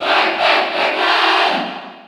Category:Crowd cheers (SSB4) You cannot overwrite this file.
Pac-Man_Cheer_French_NTSC_SSB4.ogg.mp3